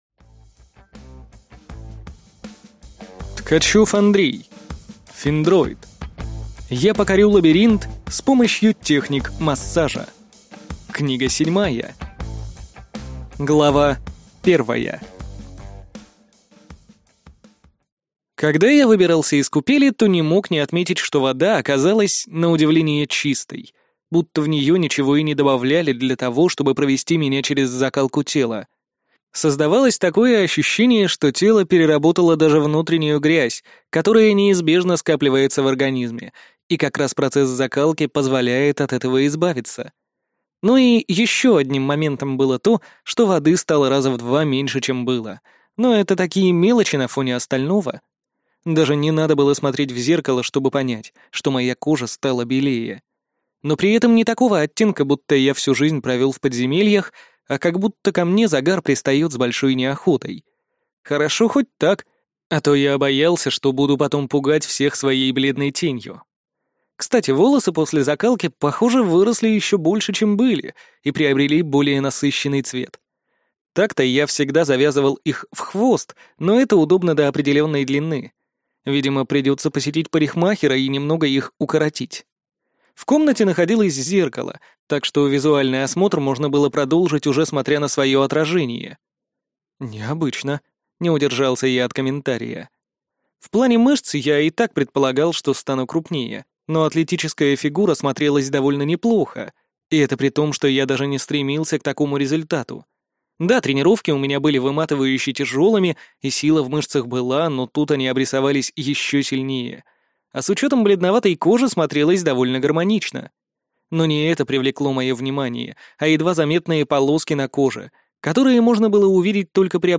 Аудиокнига Я покорю Лабиринт с помощью техник массажа. Книга 7 | Библиотека аудиокниг